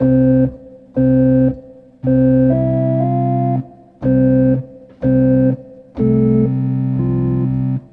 Â  And a 12obpm loop
OrganDay8_end_loop.mp3